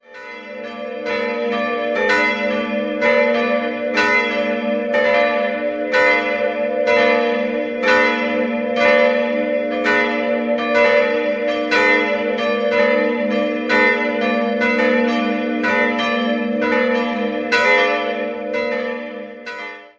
3-stimmiges Gloria-Geläute: a'-h'-d''